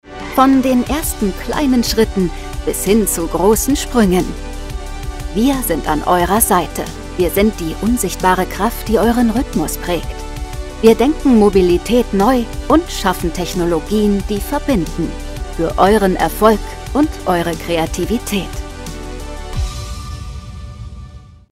Sprecherin für Werbung, Imagefilme, Hörbücher, Dokumentationen und Co.
Sprechprobe: Industrie (Muttersprache):